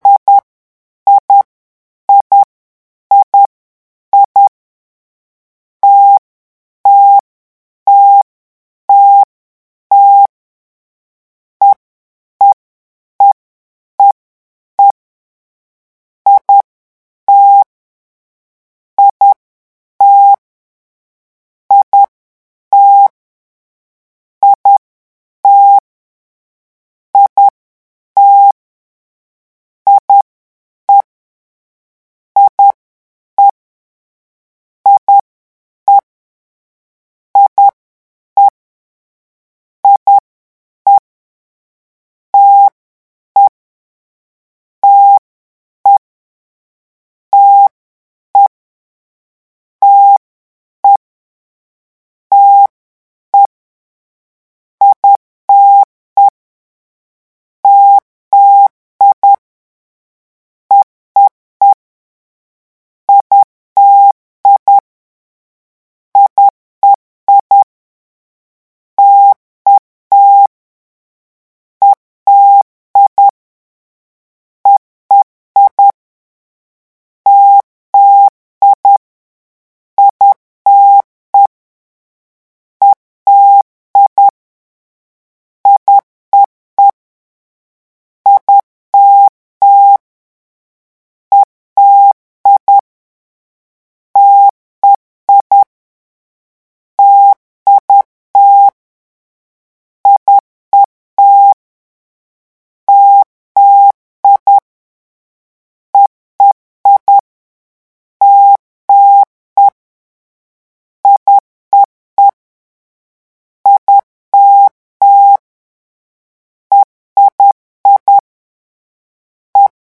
CODE MORSE-LEÇON 8
vitesse de 10 mots minute :
lecon8-vitesse_10.mp3